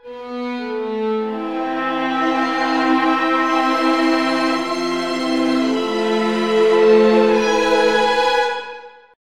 Music
chase
tinkle
march
Various short pieces of music/sound.